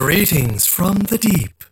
42 KB Viscous voice line (unfiltered) - Greetings from the Deep. 1